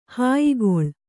♪ hāyigoḷ